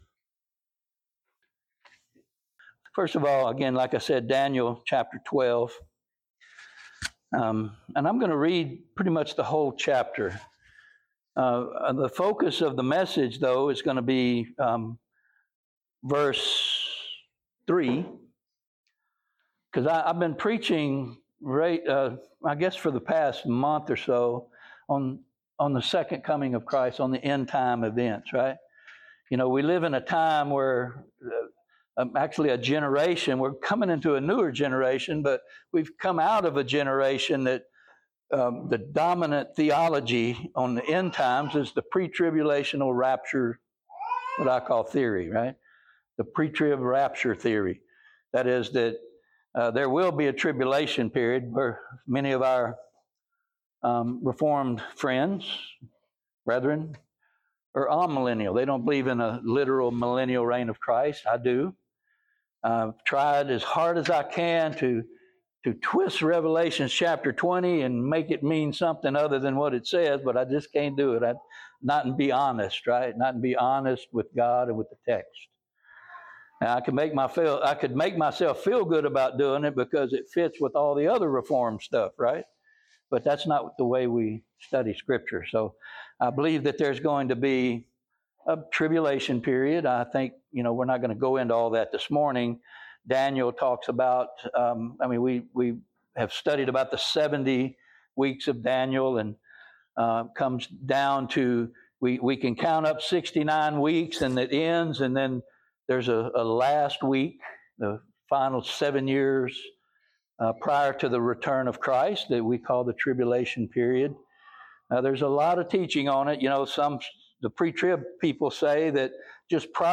Daniel 12 Service Type: Sunday Morning Topics